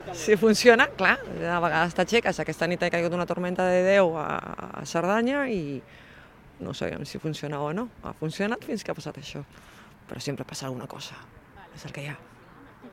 Una passatgera afectada per l’incendi a l’R3: “Hem sentit olor de cremat i hem baixat pitant del tren” ( Àudio 3 )